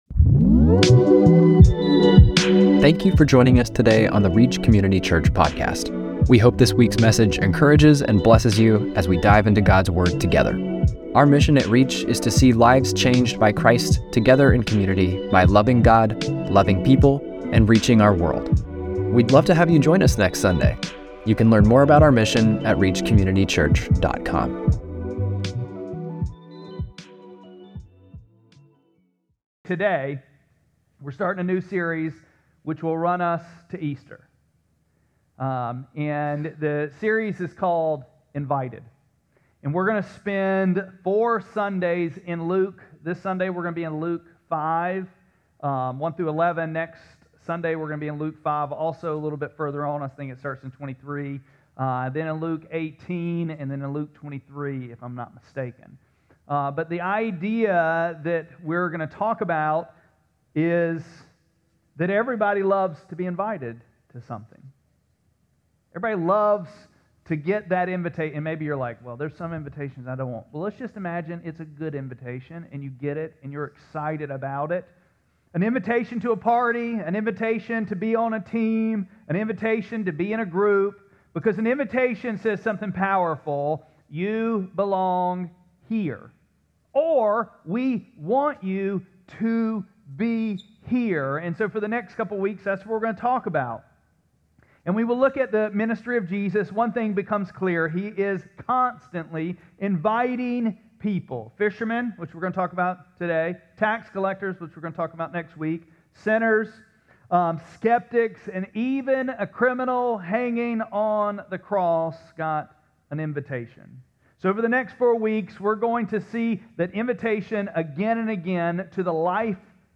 3-15-26-Sermon.mp3